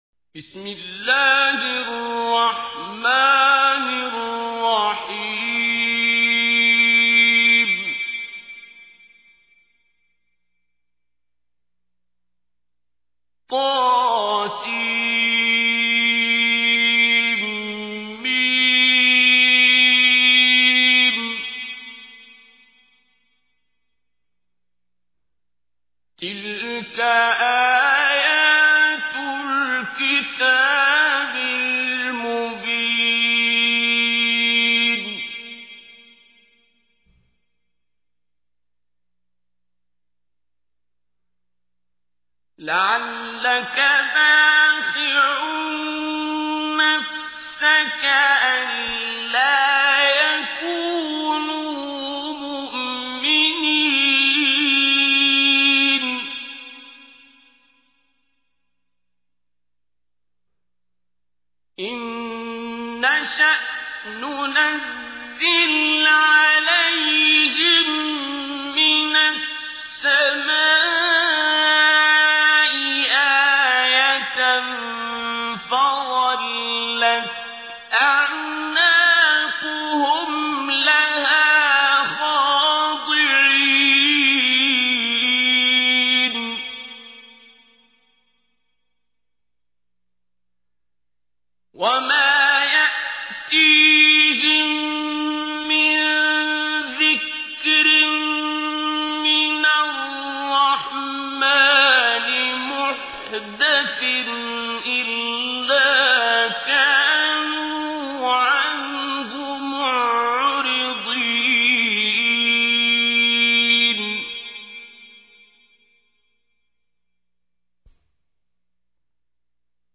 Listen online and download mp3 tilawat/Recitation of Surah Ash Shuraa in the voice of Qari Abdul Basit As Samad.